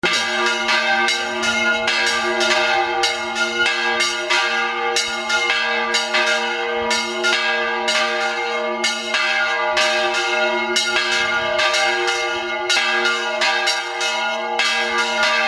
Si quieres escuchar la campana pulsa AQUÍ.
toquecampanas.mp3